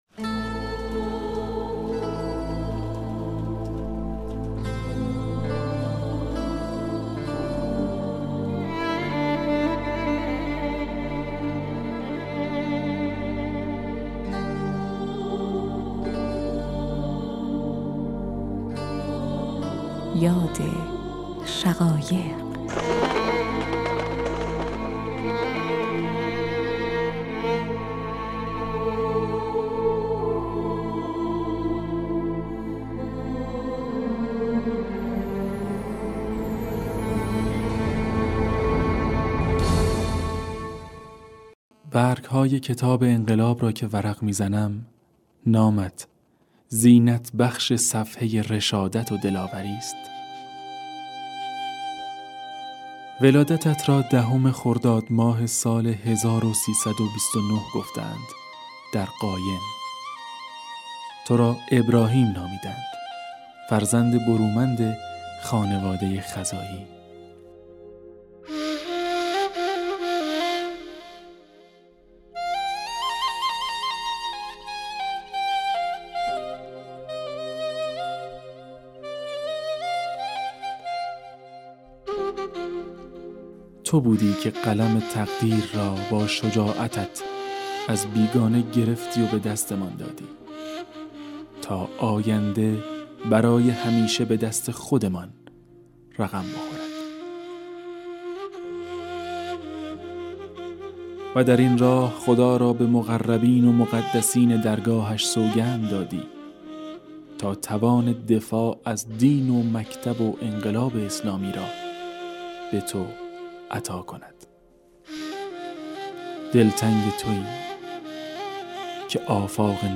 خاطره خاطره گویی خاطره ی از شهید یاد شقایق